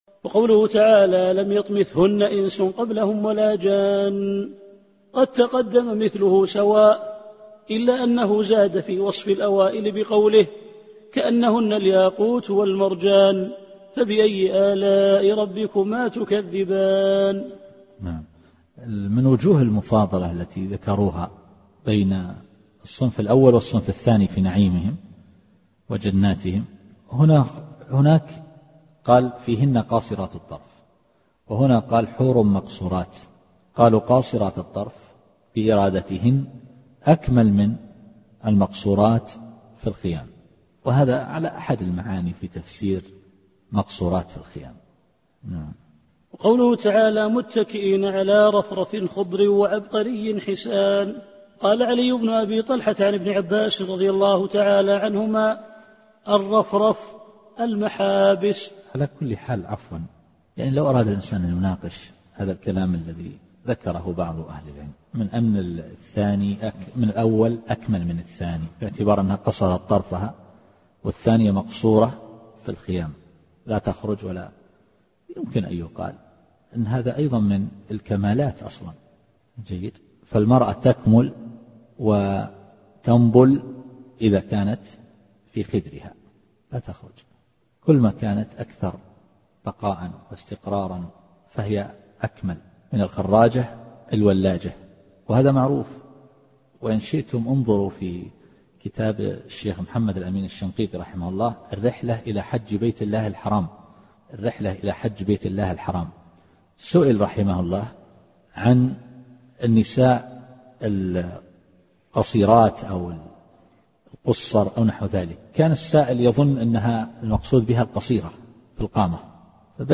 التفسير الصوتي [الرحمن / 74]